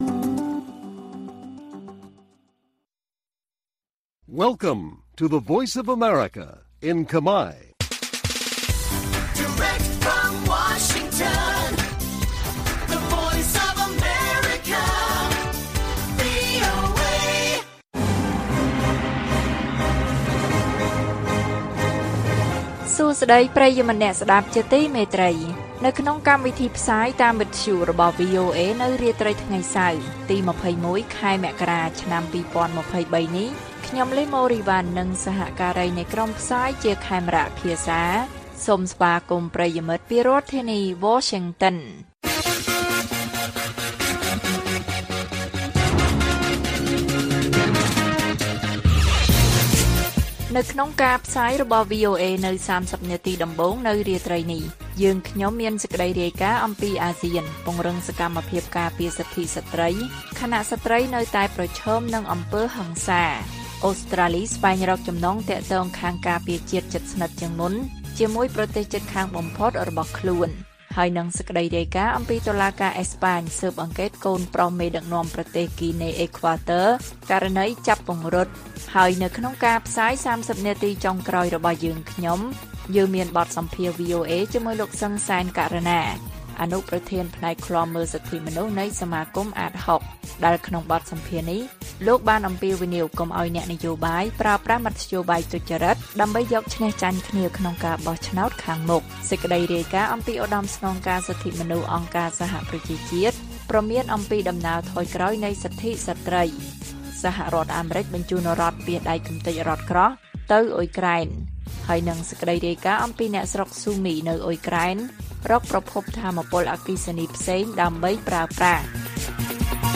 ព័ត៌មាននៅថ្ងៃនេះមានដូចជា អាស៊ានពង្រឹងសកម្មភាពការពារសិទ្ធិស្រ្តី ខណៈស្រ្តីនៅតែប្រឈមនឹងអំពើហិង្សា។ បទសម្ភាសន៍ VOA៖ អ្នកជំនាញអំពាវនាវកុំឱ្យប្រើប្រាស់មធ្យោបាយទុច្ចរិតដើម្បីយកឈ្នះចាញ់គ្នាក្នុងការបោះឆ្នោតខាងមុខ និងព័ត៌មានផ្សេងទៀត៕